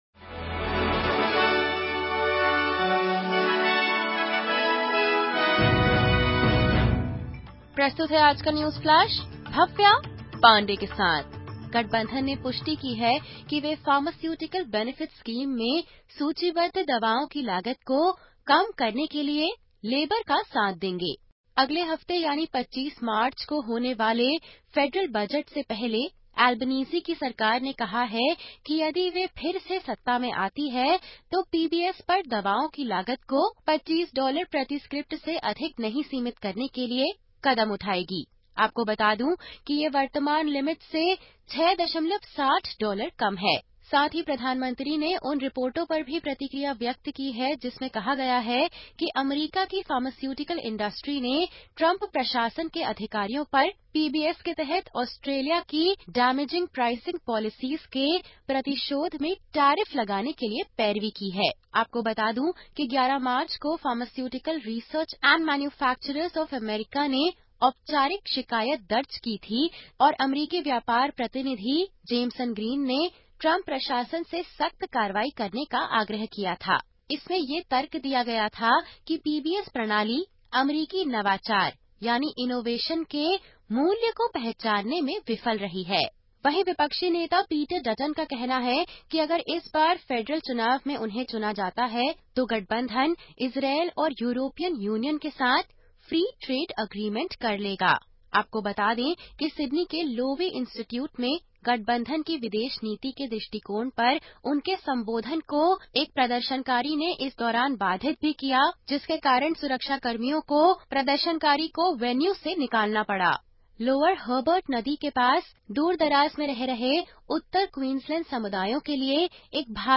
सुनें ऑस्ट्रेलिया और भारत से 20/03/2025 की प्रमुख खबरें।